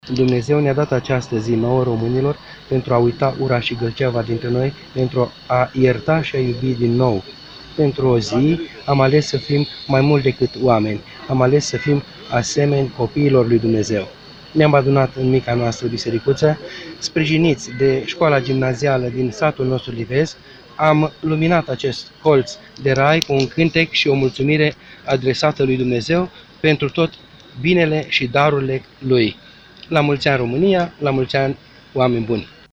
le-a vorbit enoriașilor strânși la biserică despre importanța acestei zile: